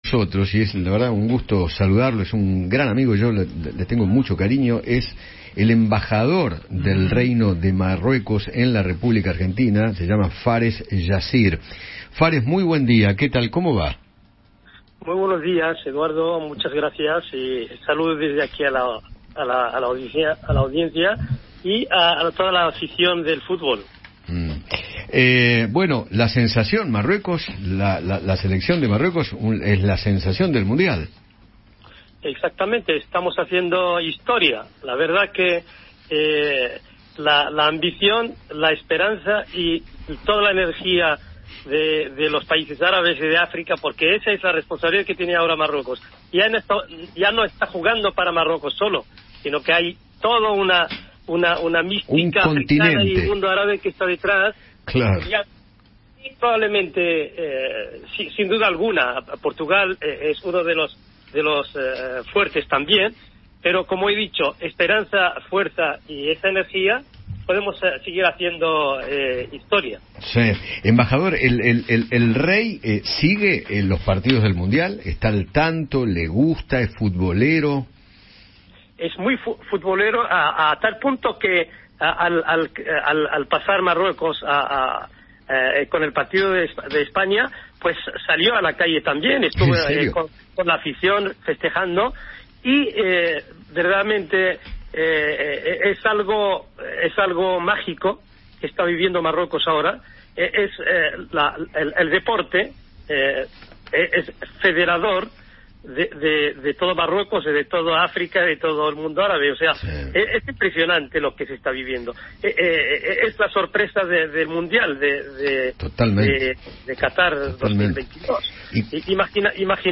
Fares Yassir, embajador del Reino de Marruecos en la Argentina, conversó con Eduardo Feinmann sobre el rendimiento de su Selección en el Mundial de Qatar 2022.